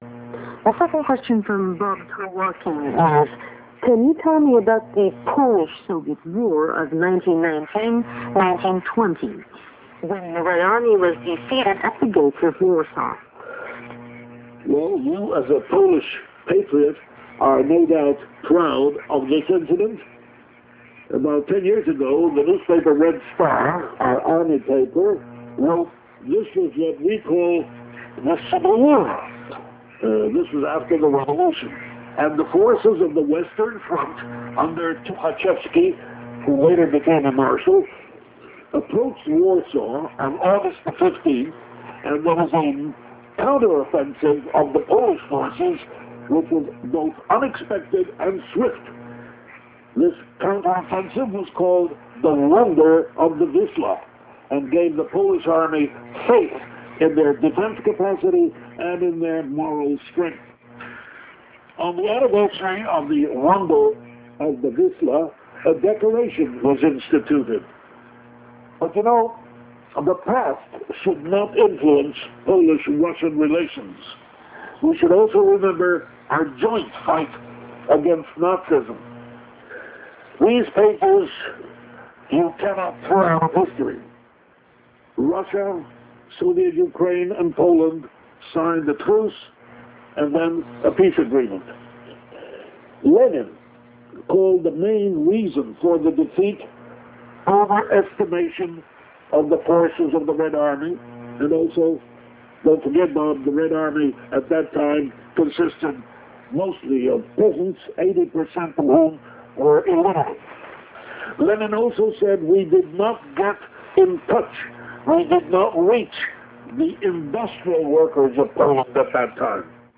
Those with access to a RealMedia Player may click the link below and hear the broadcast, delivered in Joe's own inimitable style.